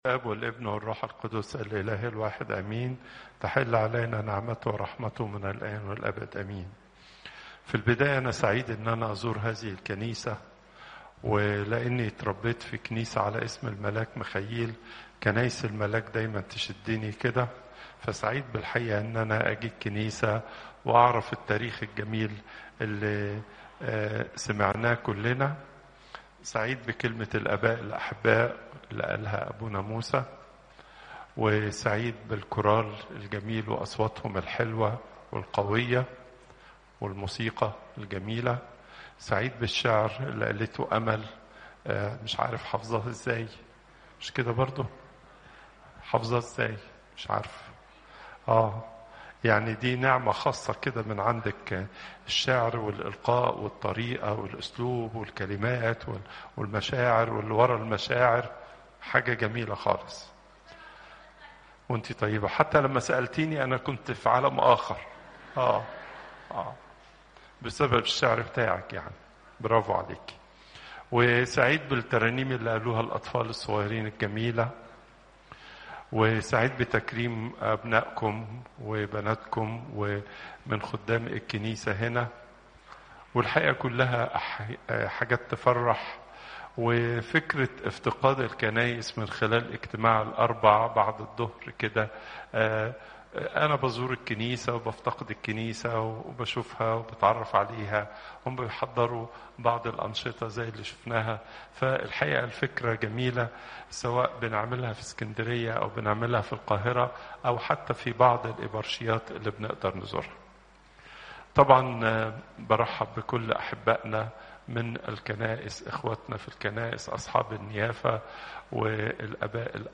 Popup Player Download Audio Pope Twadros II Wednesday, 06 November 2024 43:17 Pope Tawdroes II Weekly Lecture Hits: 7